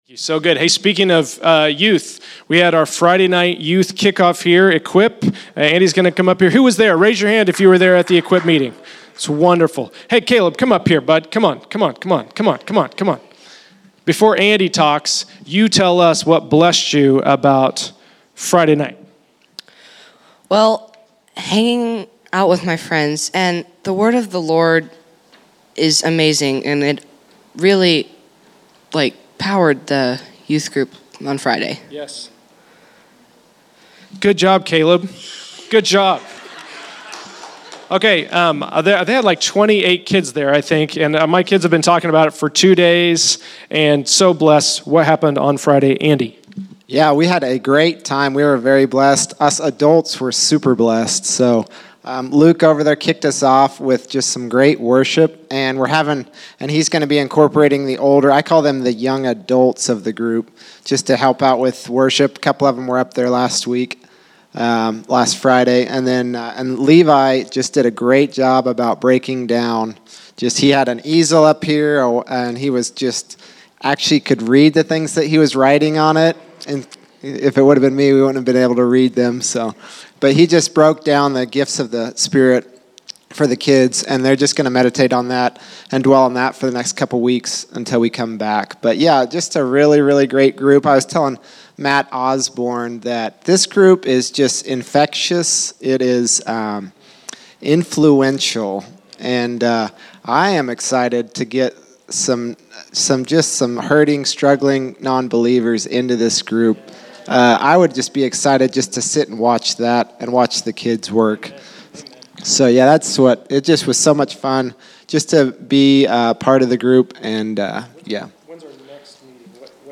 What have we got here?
Category: Report